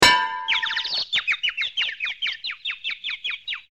WB1.25.2 HeadBonk&BirdsTwitter.mp3